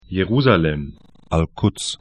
Aussprache
Jerusalem je'ru:zalɛm Al Quds al'kʊts ar Stadt / town 31°46'N, 35°14'E